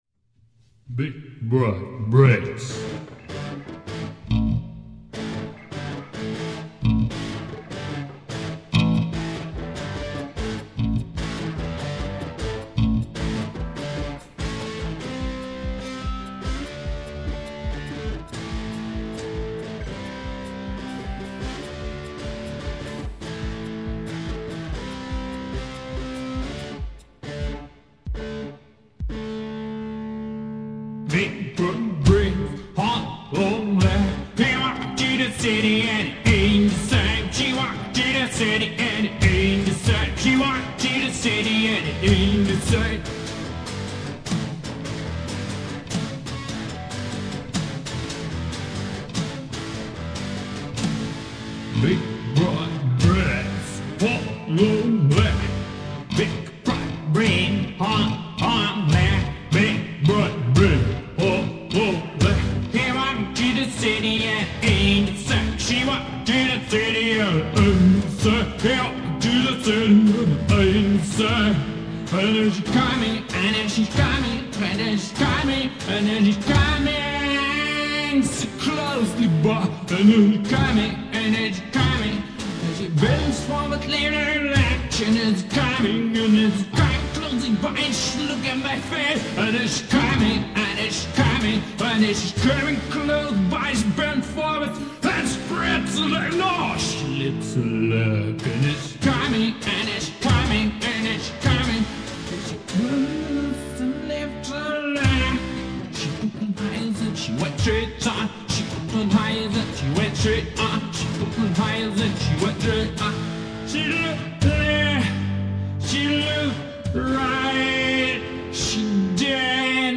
Rock/Blues MP3